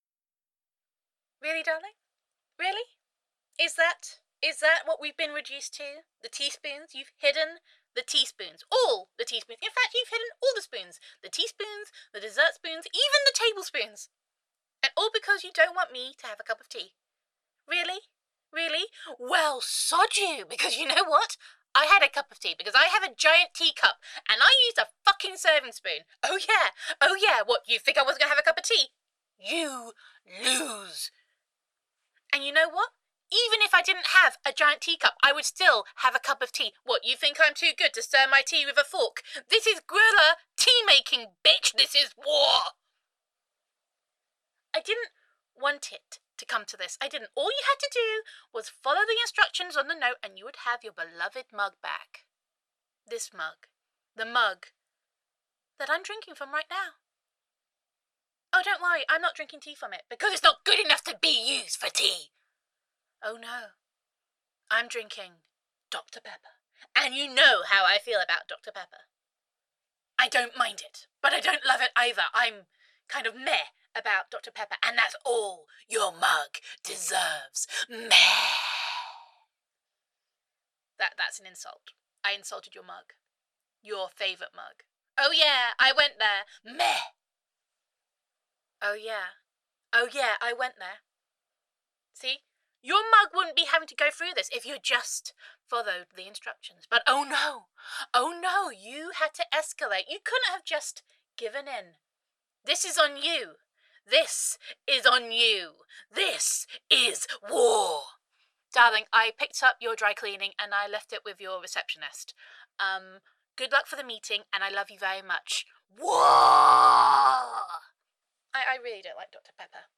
[F4A] War Can Be Brewtal [Guerrilla Tea Making][Meh!][Prisoner of War][Teapot War Two][Girlfriend Voicemail][Gender Neutral][The Teapot War Is Escalating]